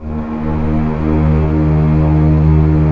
Index of /90_sSampleCDs/Roland LCDP13 String Sections/STR_Orchestral p/STR_Orch. p Slow